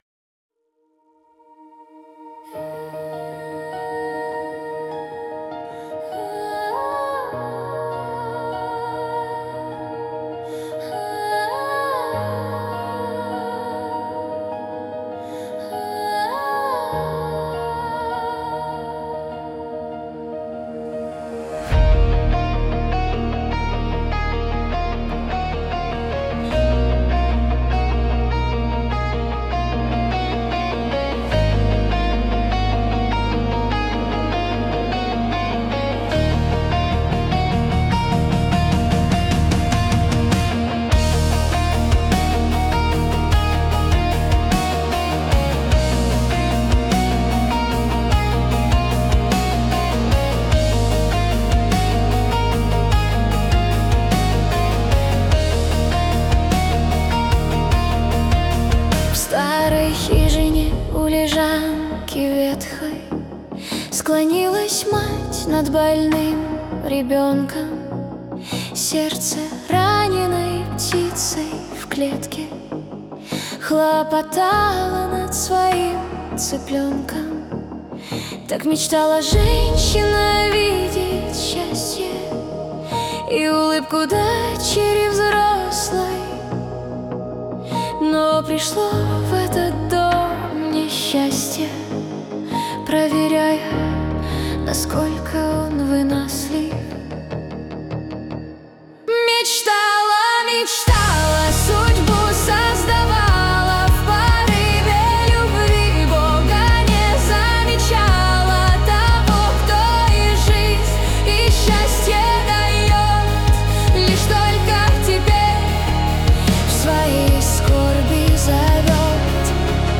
песня ai
178 просмотров 690 прослушиваний 43 скачивания BPM: 150